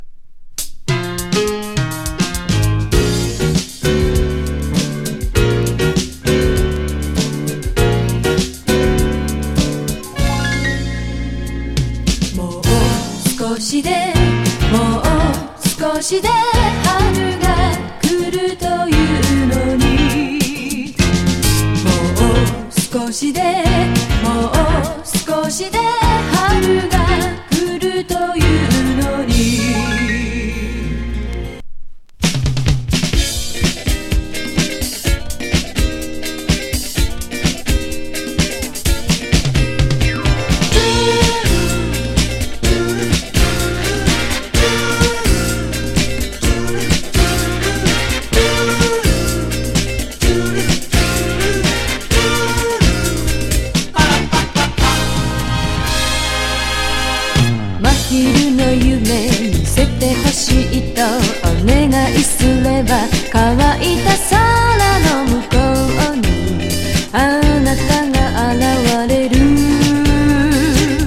グルーヴィ・ファンク・フォーキー
タイト・ディスコ歌謡